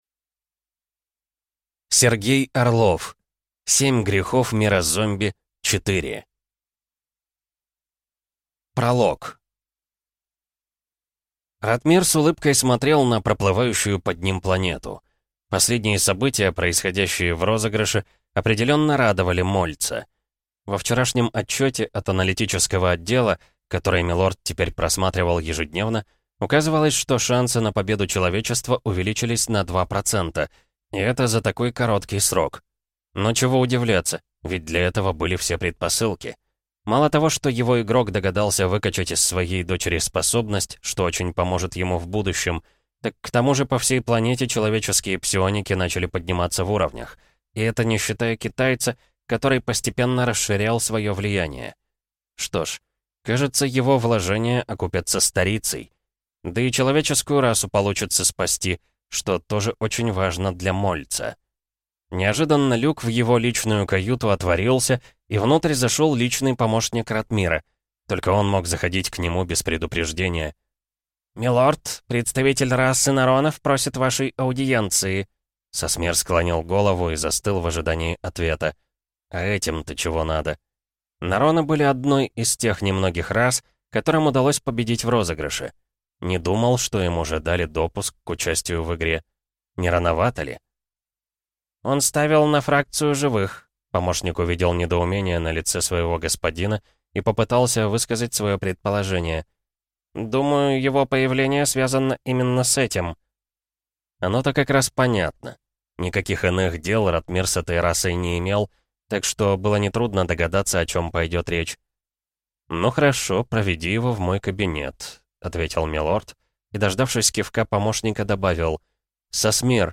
Aудиокнига Семь грехов мира ЗОМБИ-4